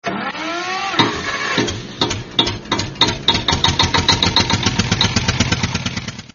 Alter Traktor Starten Sound als Klingelton kostenlos downloaden
Klingelton Traktor (Starten Sound)
Mit diesem realistischen Sound wird jedes Telefonat zu einem besonderen Erlebnis.
klingelton-traktor-starten-sound-de-www_tiengdong_com.mp3